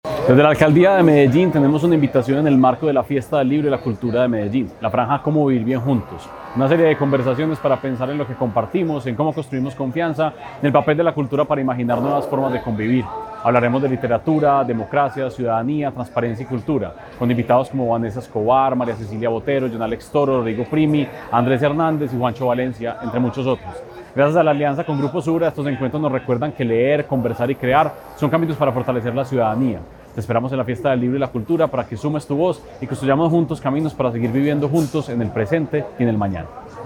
Declaraciones-del-secretario-de-Cultura-Ciudadana-Santiago-Silva.-Fiesta-del-Libro-2025.mp3